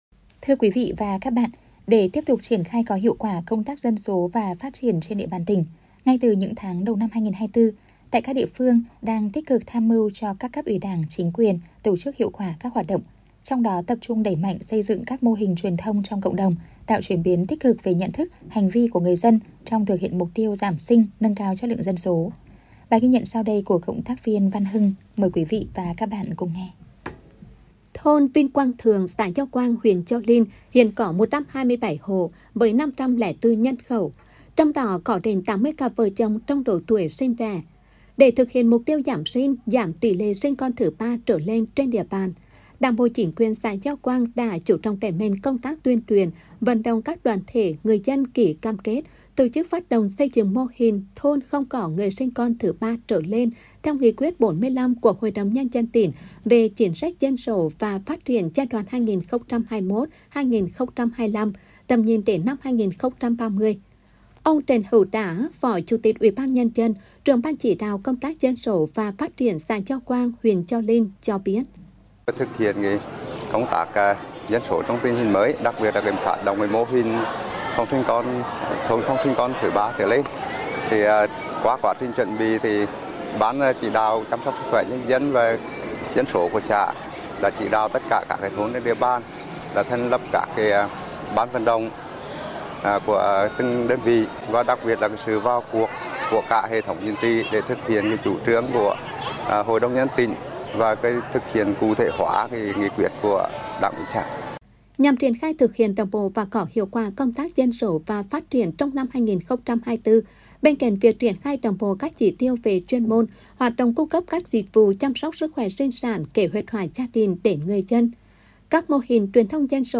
phát thanh mô hình gio quang 2024